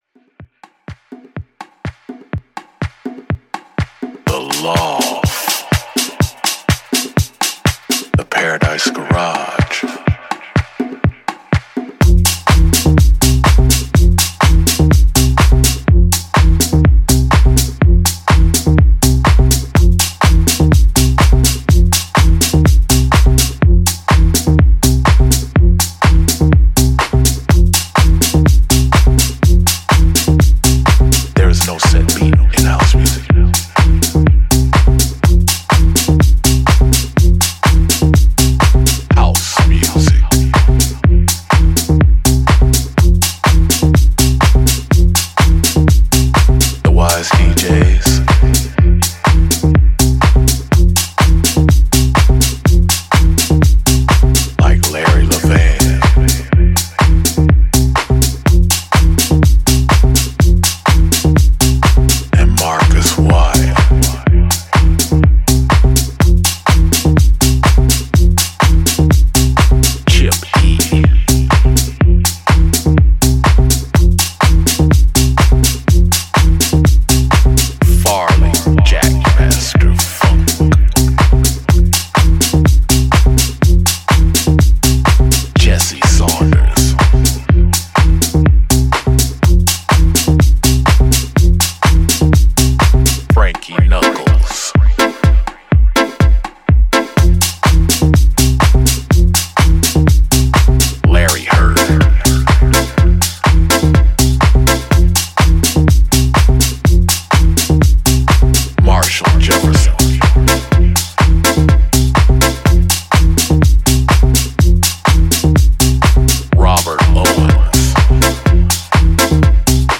ジャンル(スタイル) HOUSE / RE-EDIT